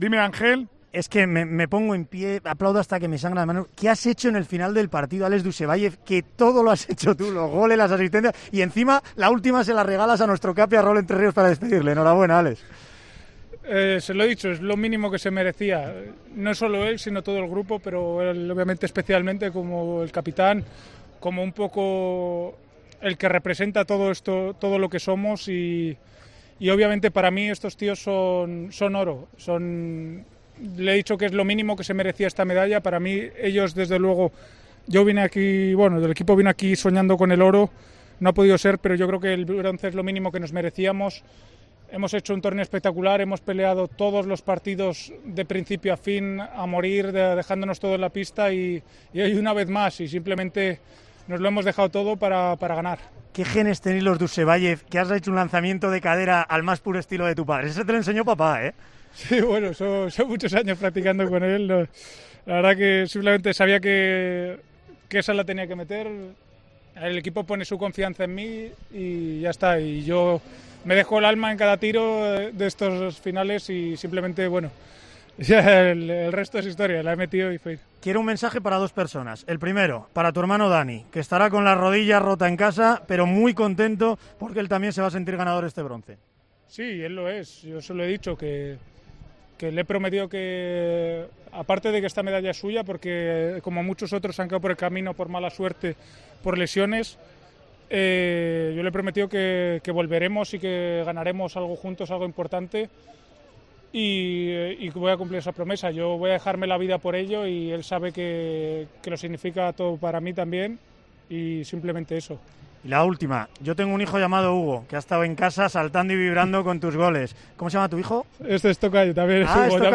El jugador de la selección de balonmanoa